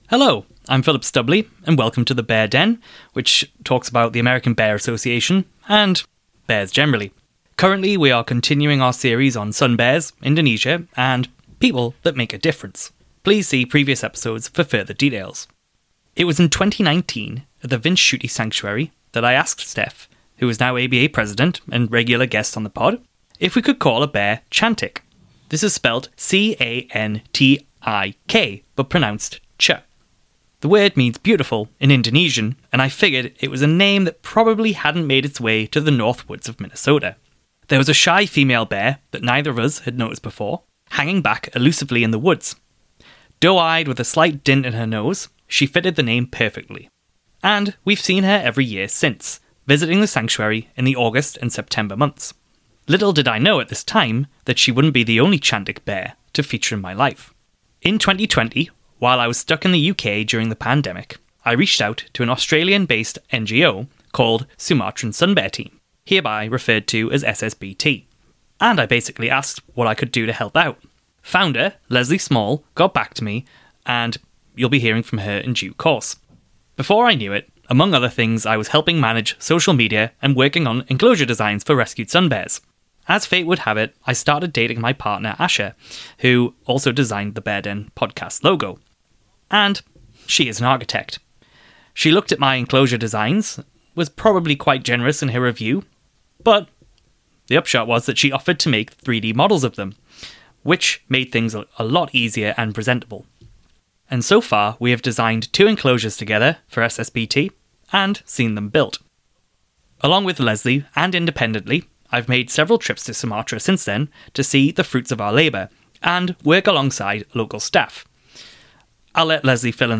Episode 25 – Relentless Snares: A chat with Sumatran Sun Bear Team